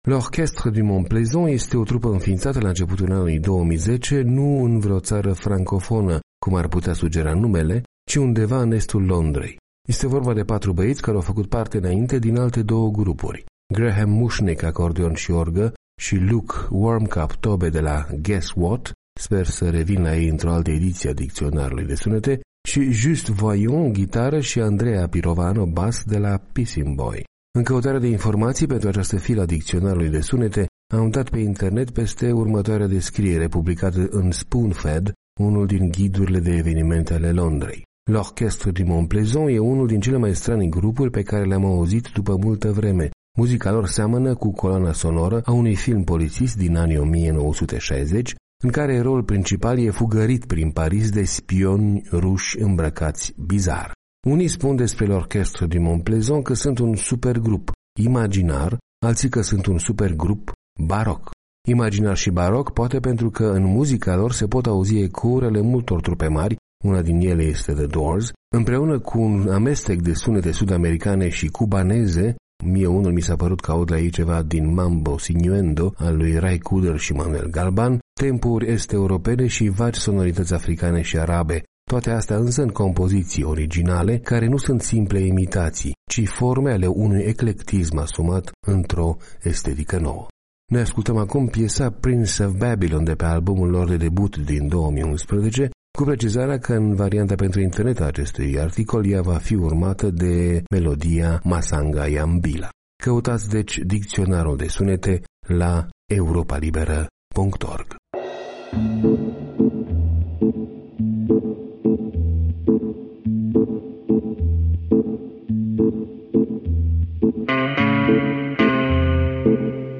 Muzica lor seamănă cu coloana sonoră a unui film polițist din anii 1960, în care eroul principal e fugărit prin Paris de spioni ruși îmbrăcați bizar.